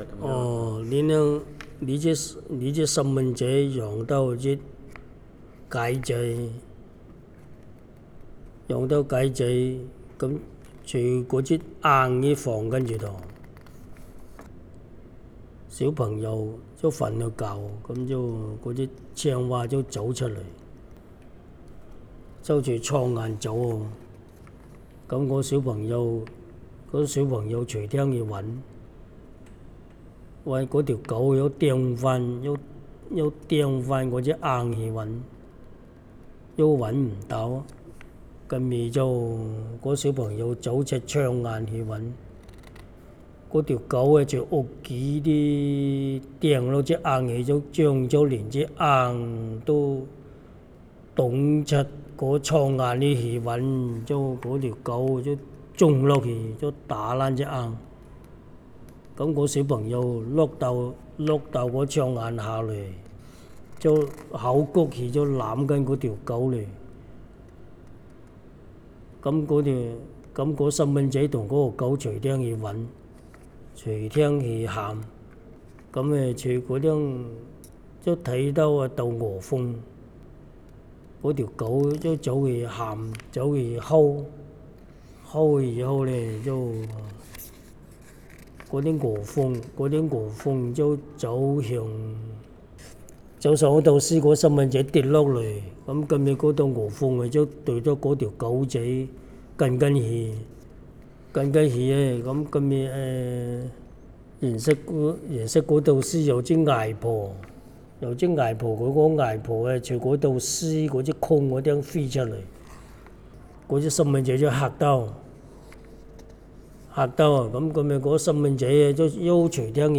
Male, 67
digital wav file recorded at 44.1 kHz/16 bit on Zoom H2 solid state recorder
Dapeng, Shenzhen, Guangdong Province, China
Dapeng dialect in Shenzhen, China